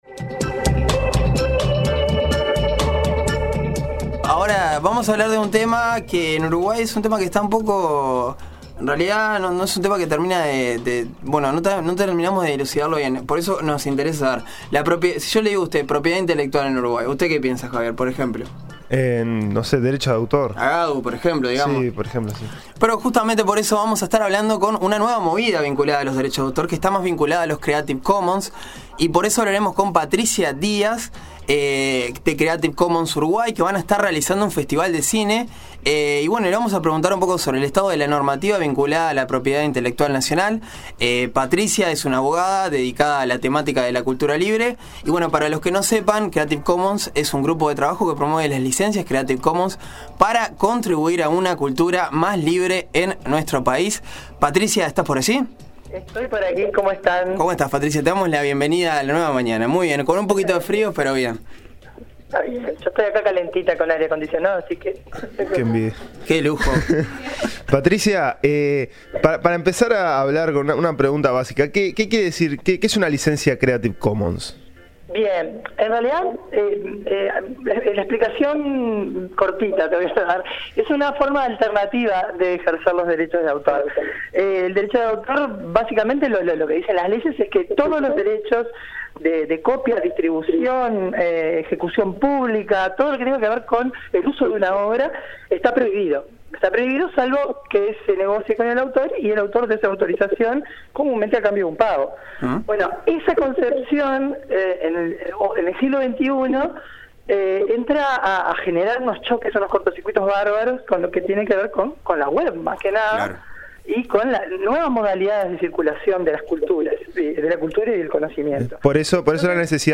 dialogó con La Nueva Mañana y nos estuvo contando sobre nuevas modalidades en materia de derechos de autor. La Licencia Creative Commons, en su opinión, es una forma alternativa de ejercer el derecho de autor, que en el siglo XXI es problematizada desde la realidad de la web.